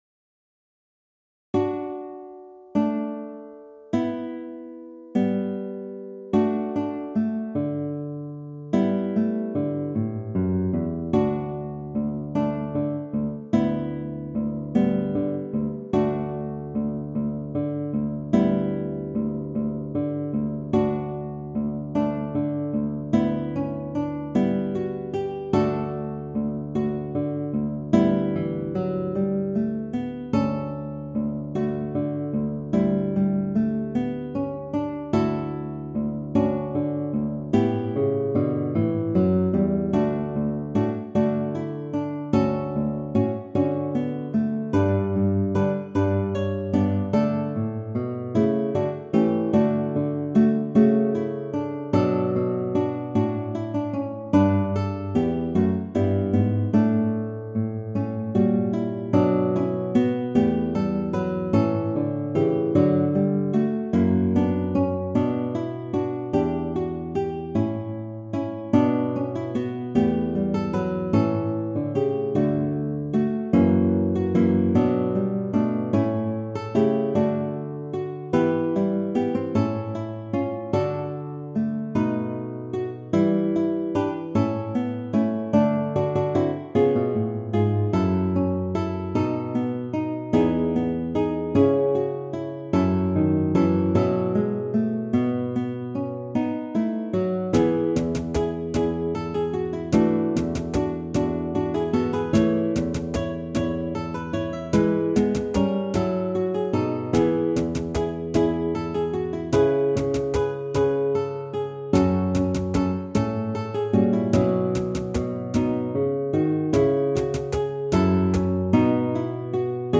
Neue Musik
Ensemblemusik
Quartett
Gitarre (4)